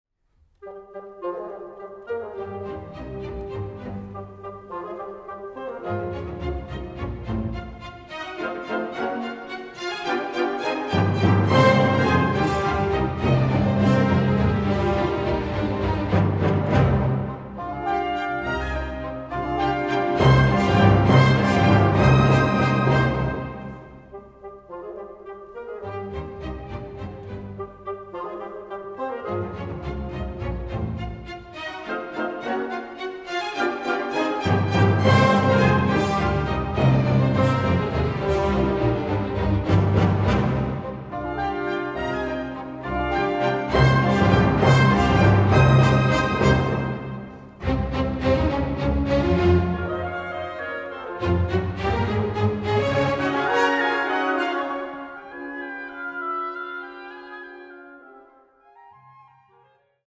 ein großes Orchester
Der Live-Mitschnitt zeigt